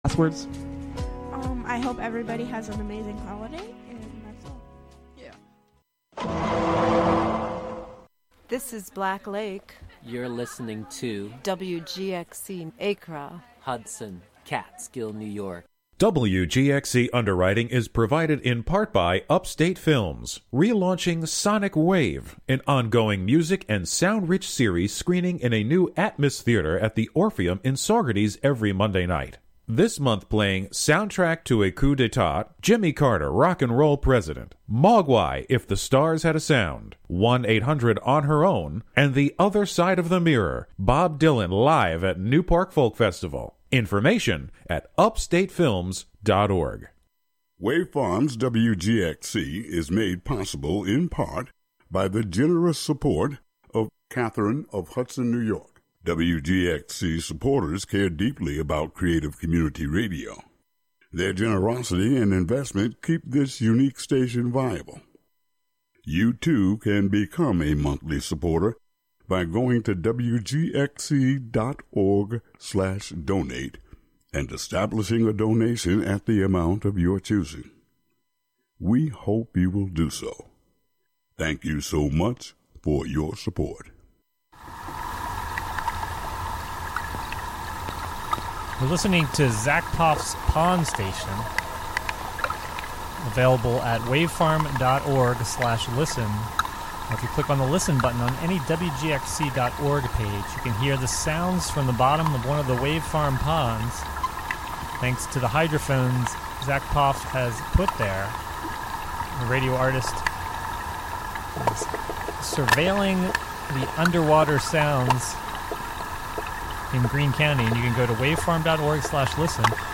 Featuring gospel, inspirational, soul, R&B, country, christian jazz, hip hop, rap, and praise and worship music of our time and yesteryear, interwoven with talk, interviews, and spiritual social commentary